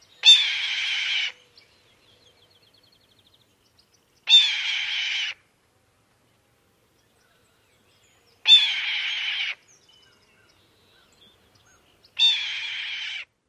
红尾鵟刺耳的嘶叫声